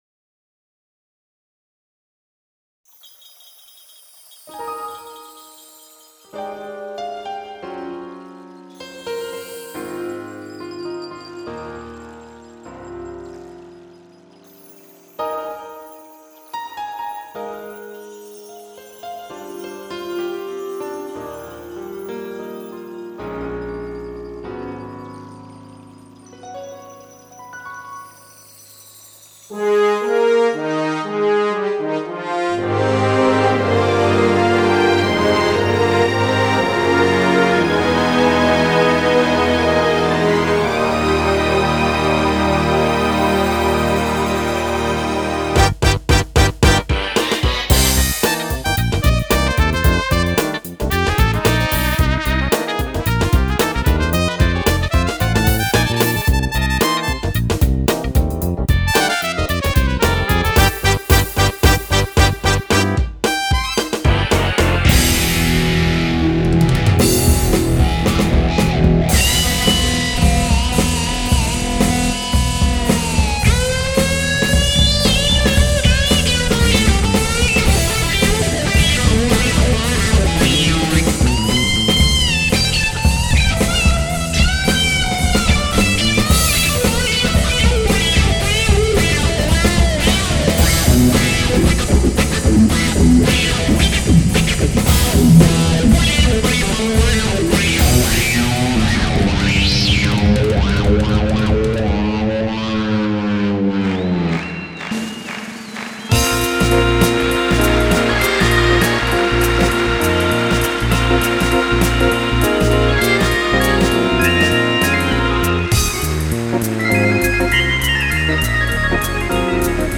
↑デモサウンド
同時発音数 128音
出だしのピアノと、それに掛かっているリバーブによる世界観の広がりがすばらしい。
クラッシュシンバルも、ようやく頑張れる音色になったかと思います。